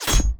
impact_projectile_metal_002.wav